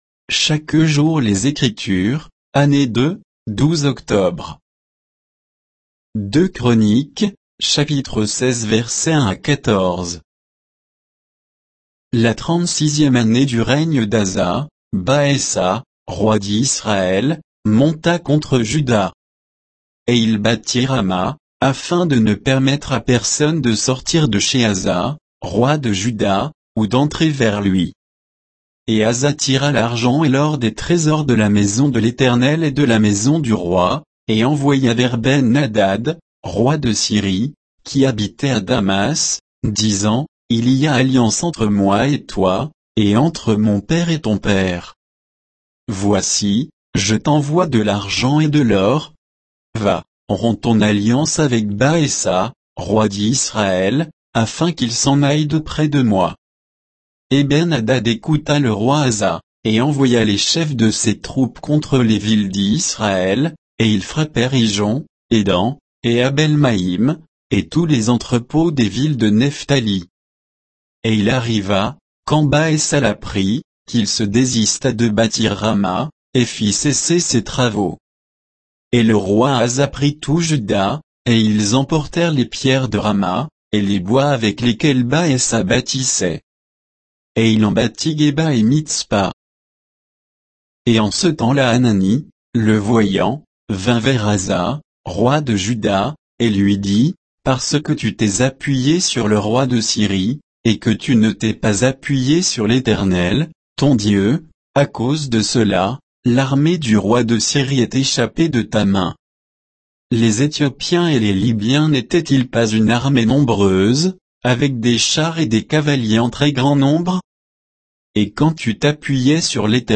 Méditation quoditienne de Chaque jour les Écritures sur 2 Chroniques 16, 1 à 14